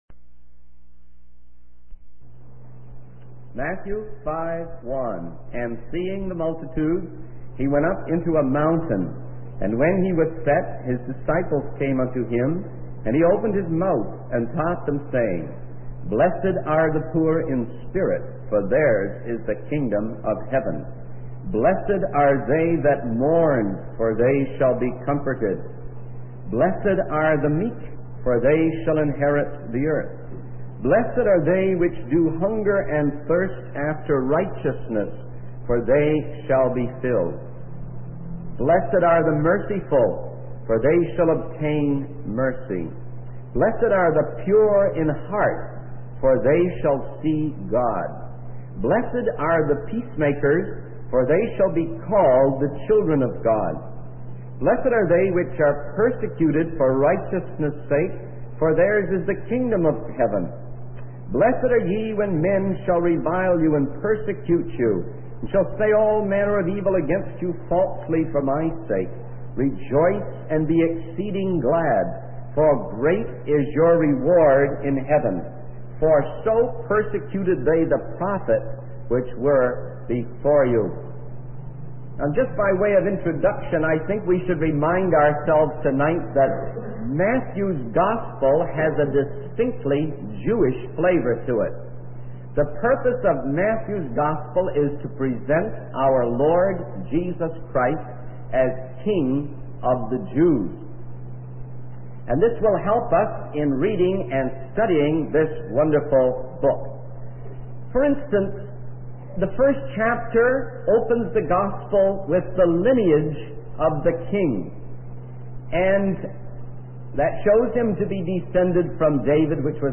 In this sermon, the preacher discusses the teachings of Jesus in the Sermon on the Mount. He emphasizes that these teachings are humanly impossible to follow, highlighting the need for the supernatural power of the Holy Spirit in the Christian life.